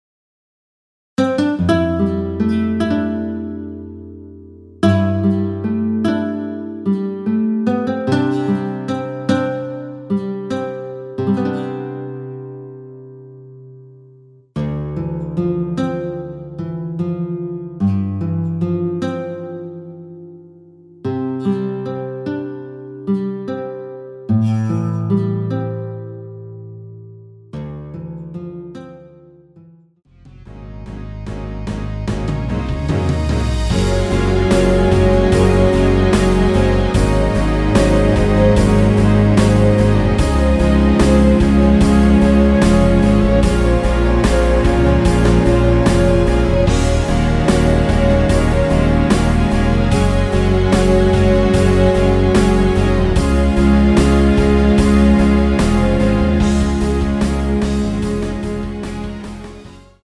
원키에서(-4)내린 MR입니다.
앞부분30초, 뒷부분30초씩 편집해서 올려 드리고 있습니다.
중간에 음이 끈어지고 다시 나오는 이유는